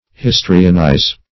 Search Result for " histrionize" : The Collaborative International Dictionary of English v.0.48: Histrionize \His"tri*o*nize\, v. t. To act; to represent on the stage, or theatrically.
histrionize.mp3